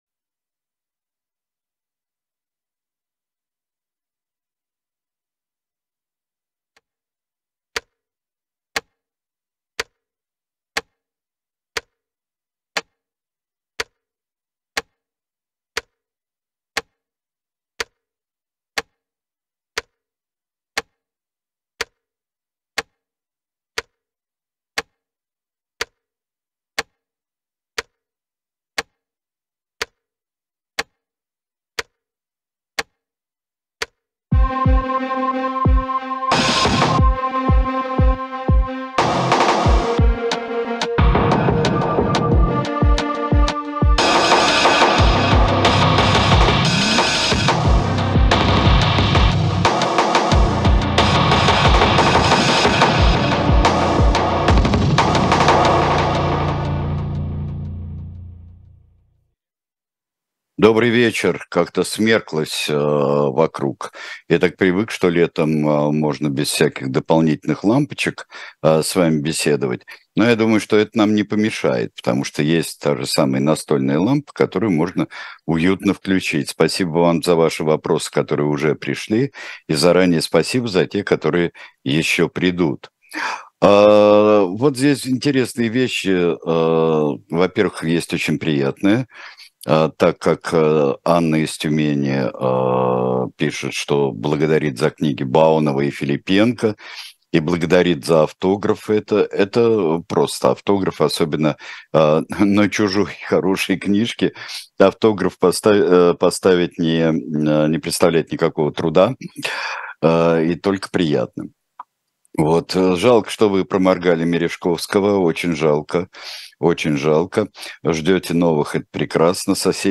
Сергей Бунтман отвечает на ваши вопросы в прямом эфире.